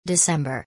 • آخرین ماه میلادی December است که به شکل /dəˈsembər/ تلفظ می‌شود و در فارسی به آن دسامبر می‌گویند.